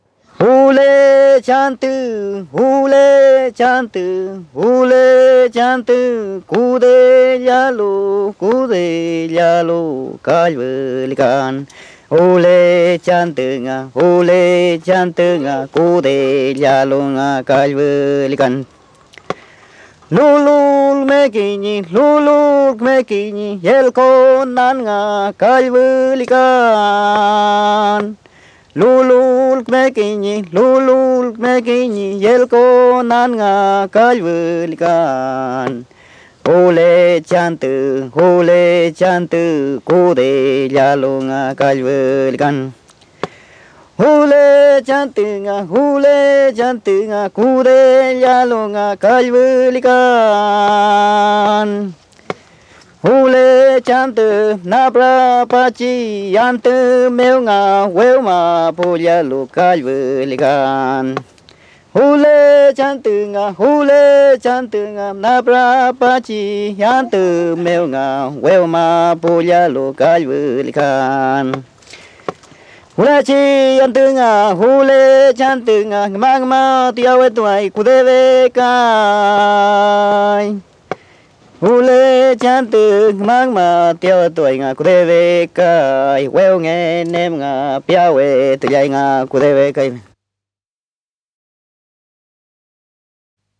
Música mapuche (Comunidad Quetrahue, Lumaco)
Música vocal
Música tradicional